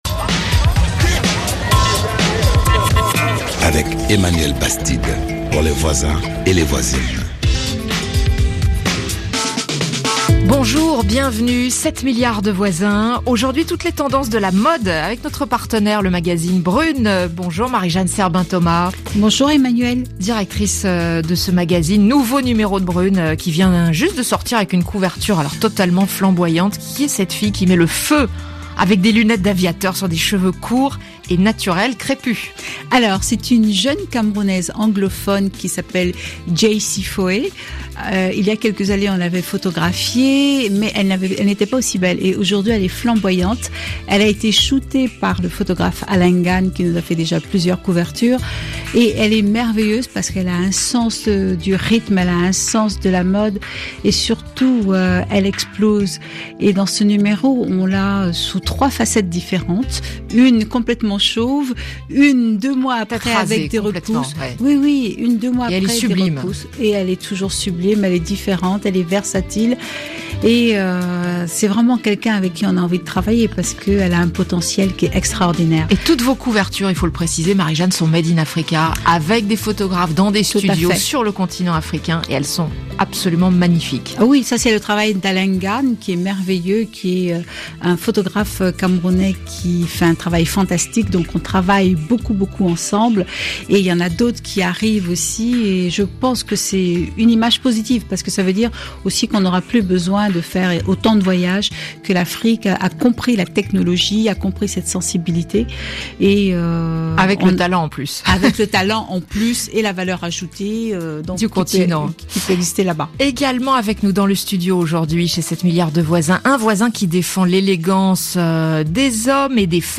– Ecouter une jeune créatrice parler de sa marque – Décrire en détail le style d’une marque et de sa créatrice 3.
Chronique 8 milliards de voisins